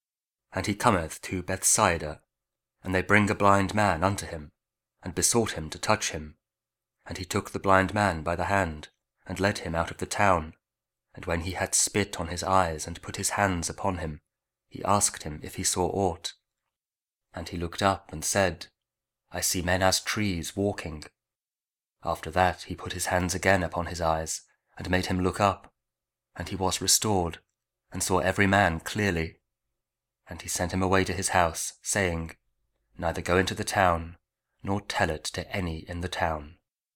Mark 8: 22-26 – Week 6 Ordinary Time, Wednesday (Audio Bible, Spoken Word)